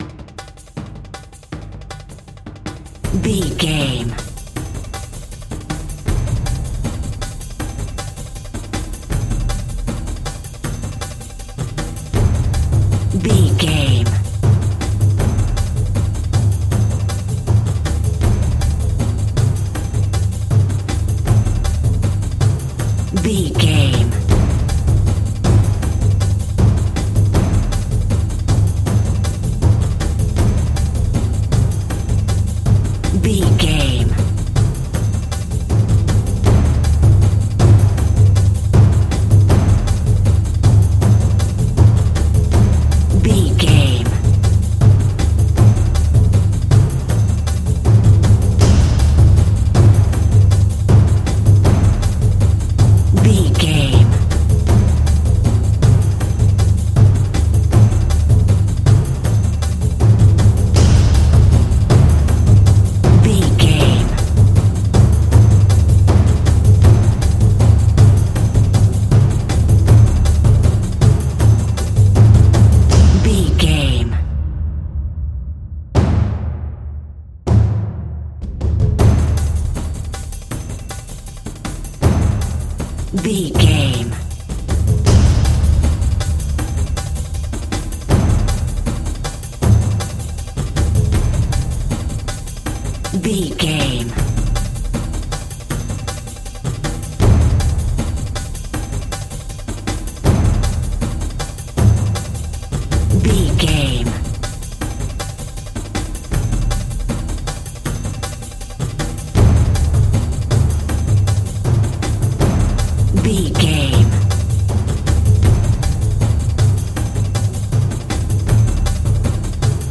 Exotic and world music!
Epic / Action
Fast paced
Atonal
Fast
world beat
percussion
taiko drums
timpani